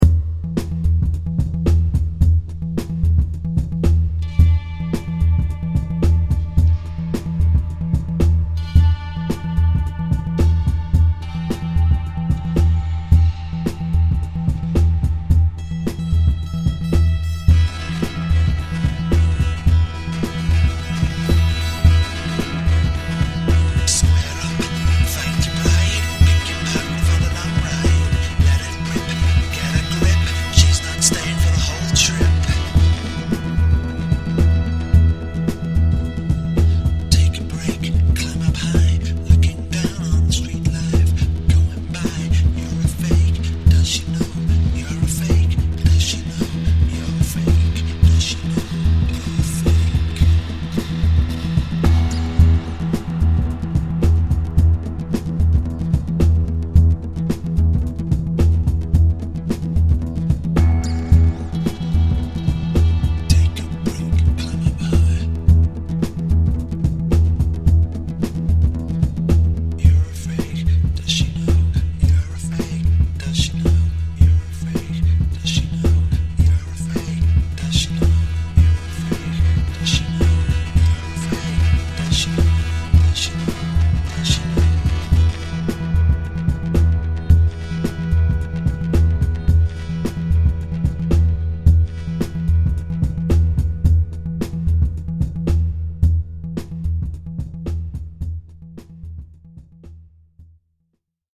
This track has nothing to do with that either, but it was written in the aftermath, as I sat watching from the rooftops with a guitar and a cup of tea as the street slowly came awake.